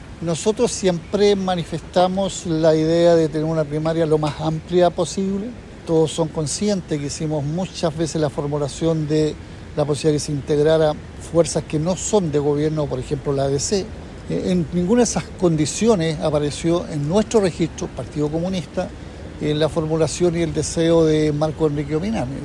Esta declaración fue refutada por el presidente del Partido Comunista, Lautaro Carmona, quien planteó que “no es responsabilidad del sector que él no haya dado un paso con mayor determinación”.